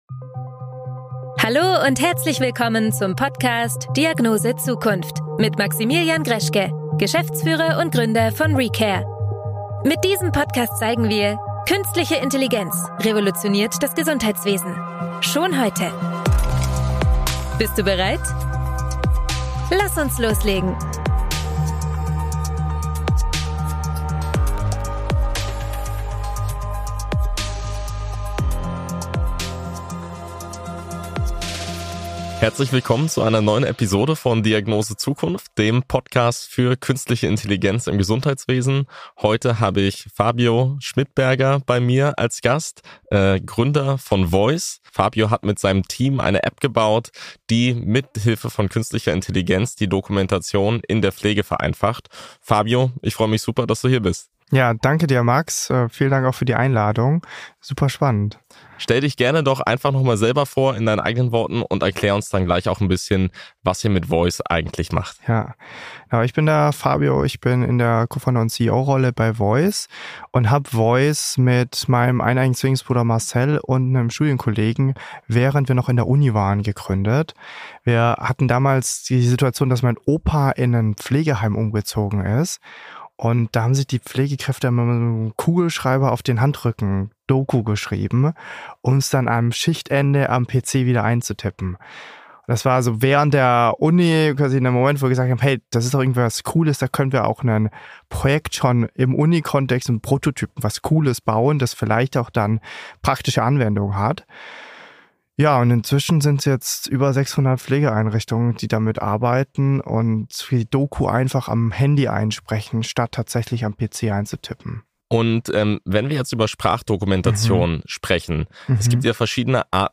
Voice-gestützte Dokumentation in der Pflegebranche - Interview